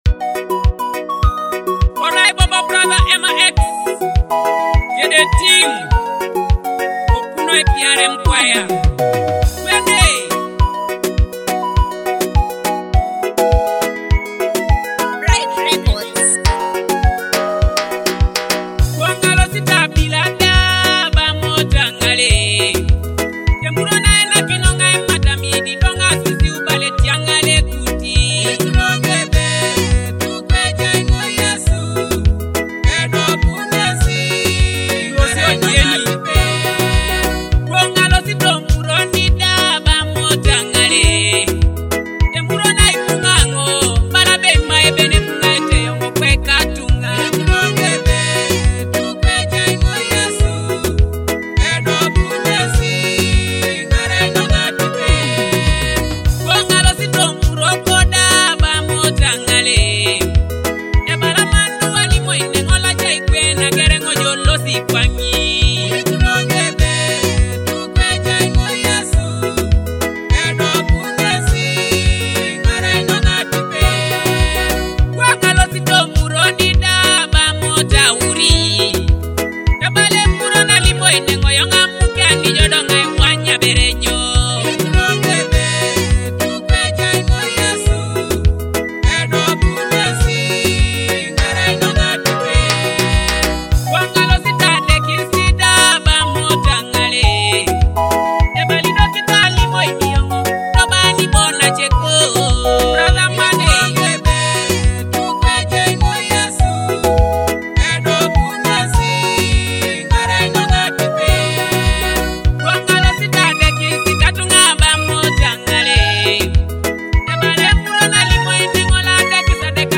uplifting gospel music